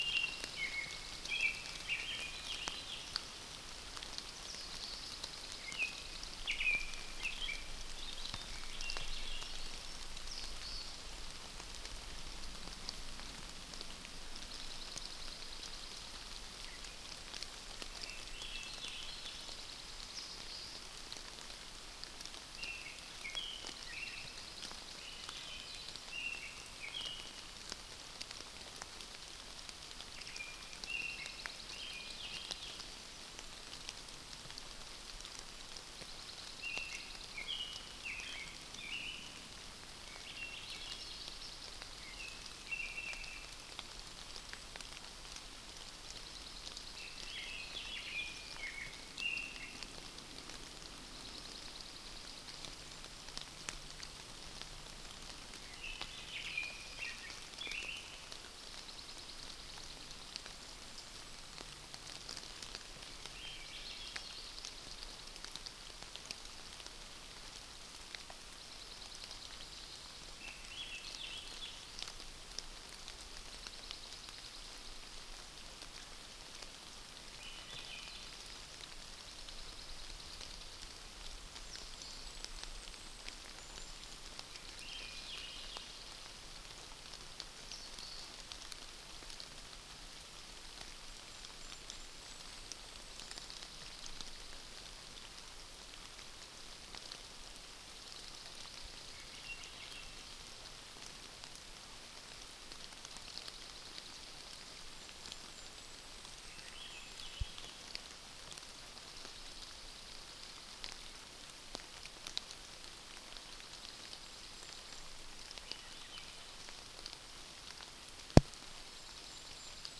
Early morning sounds at this site in Talkeetna, Alaska, on June 15, 2010